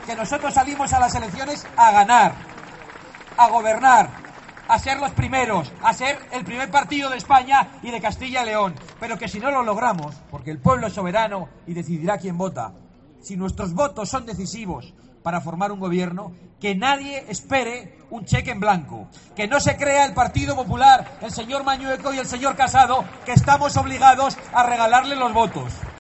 En un acto celebrado en el Mercado Chico con cientos de asistentes, Santiago Abascal, ha lamentado que Ávila y Castilla y León “sufran” y se haya convertido “en el patito feo del estado de las autonomías” debido a las políticas de Partido Popular y Partido Socialista que lejos de “premiar” su lealtad les han “castigado” mientras que los separatistas reciben “un premio permanente”.